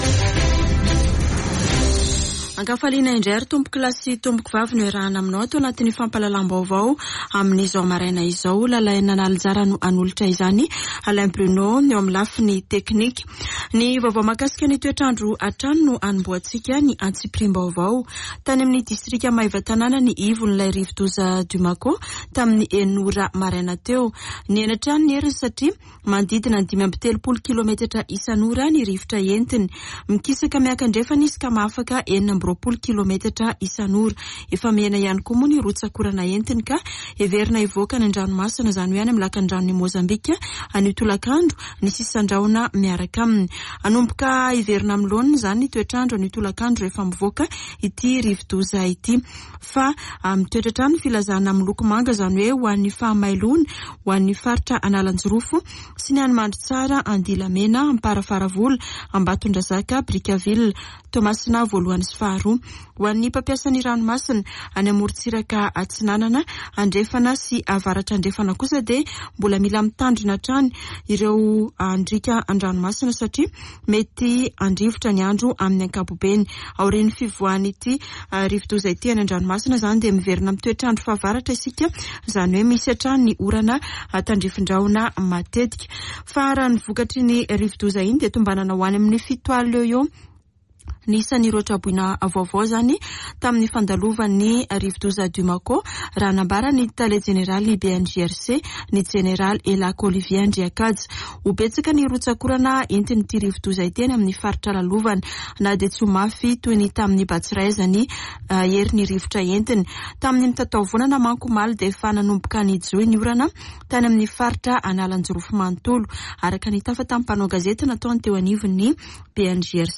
[Vaovao maraina] Alarobia 16 febroary 2022